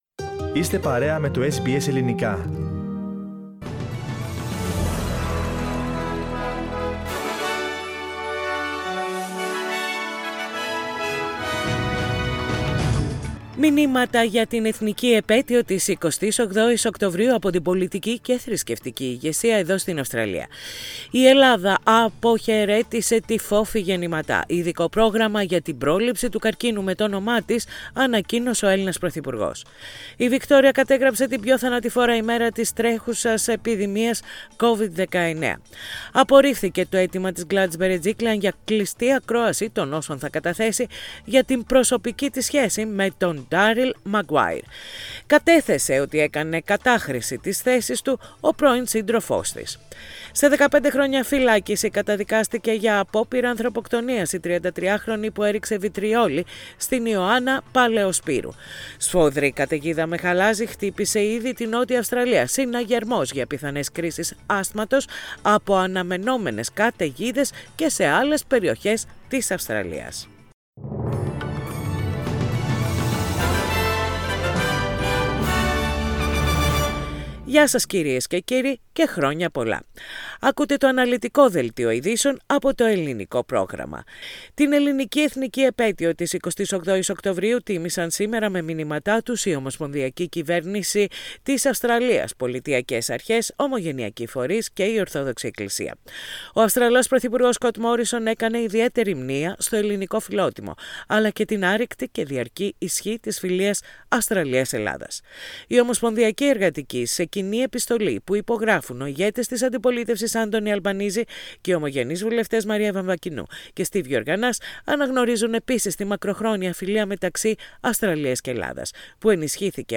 Ειδήσεις στα Ελληνικά - Πέμπτη 28.10.21